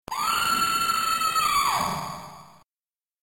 a scream from Jennifer.
splatter_jenscream.mp3